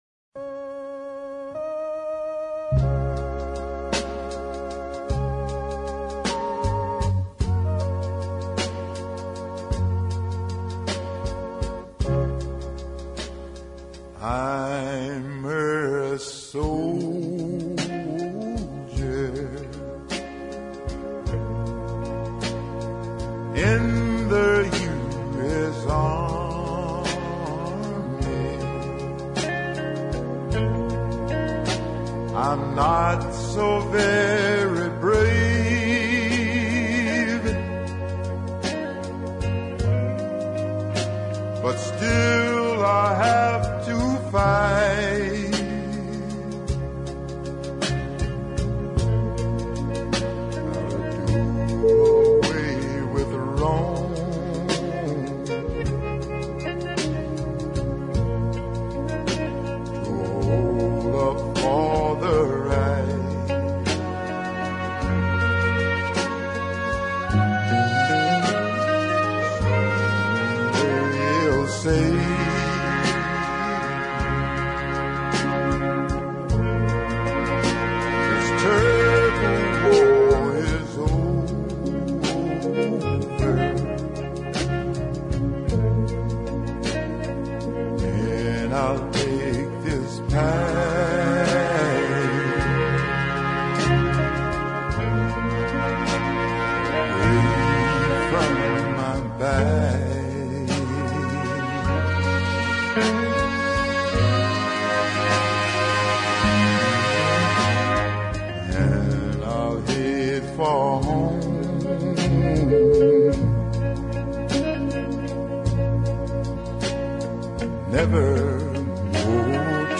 deep soul ballad